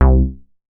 MoogRous 005.WAV